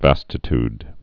(văstĭ-td, -tyd) also vas·ti·ty (-tē)